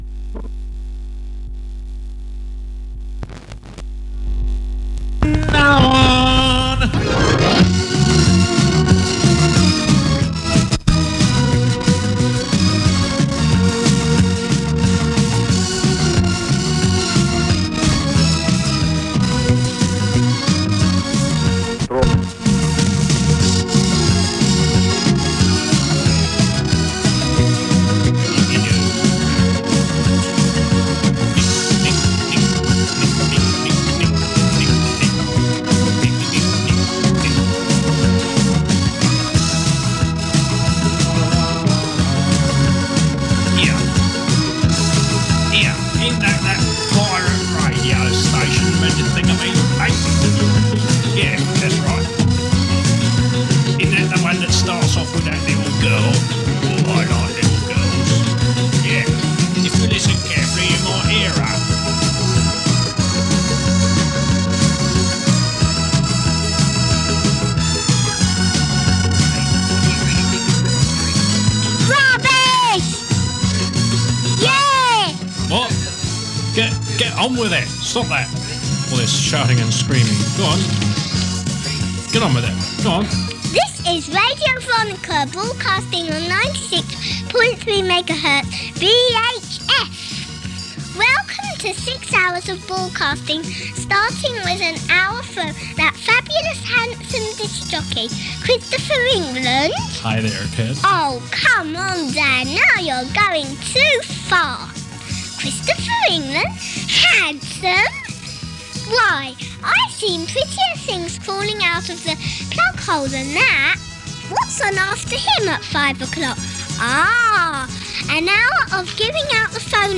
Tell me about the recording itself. Recorded in North London from 96.3MHz in mono. 62MB 45mins